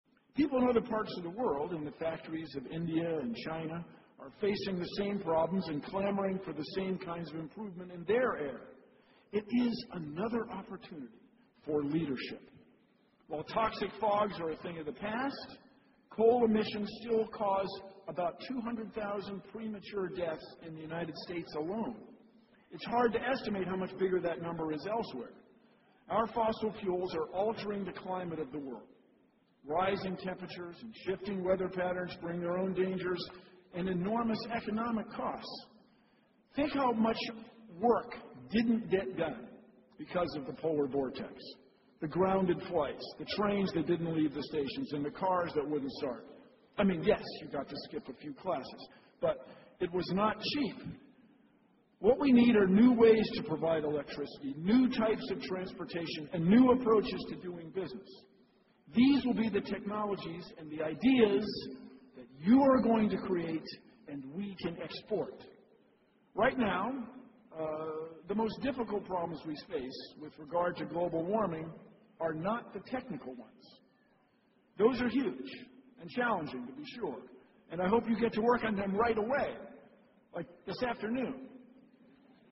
公众人物毕业演讲第161期:比尔·奈马萨诸塞大学2014(8) 听力文件下载—在线英语听力室